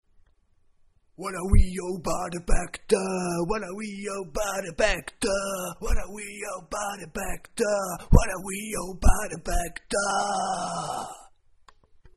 Něco jako rap nebo hip-hop.